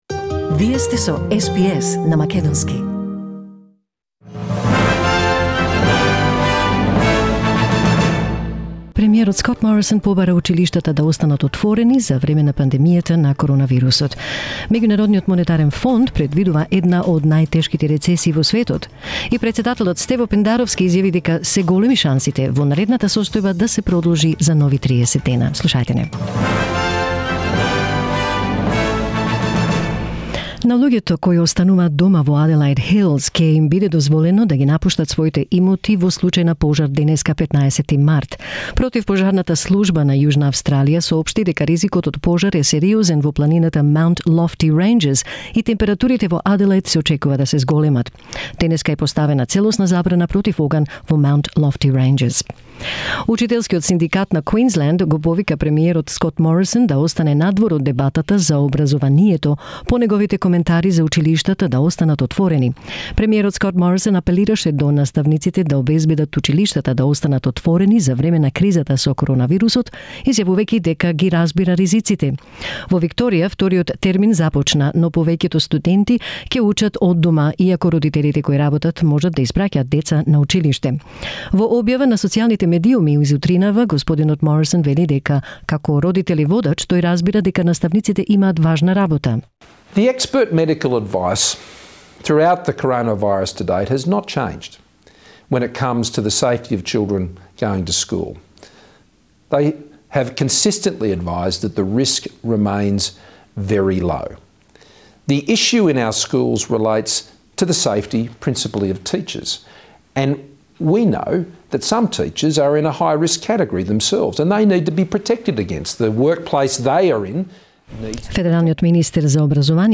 SBS News in Macedonian 15 April 2020